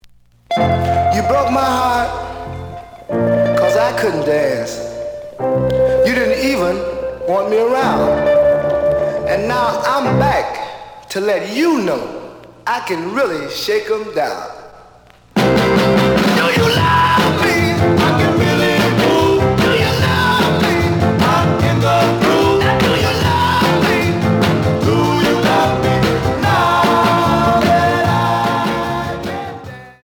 試聴は実際のレコードから録音しています。
●Format: 7 inch
●Genre: Soul, 60's Soul